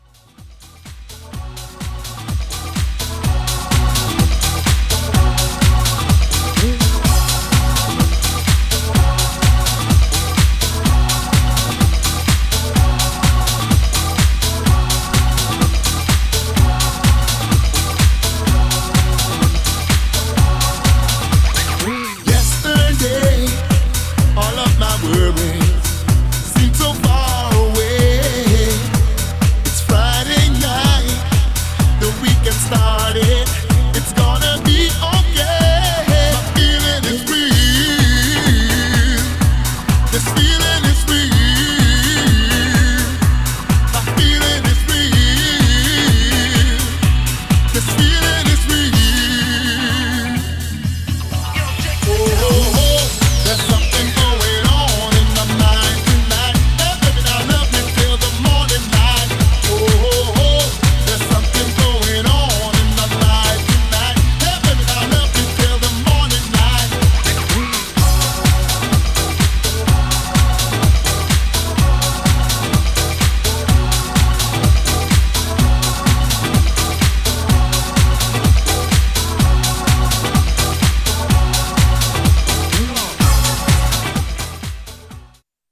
Styl: Disco, Progressive, House, Trance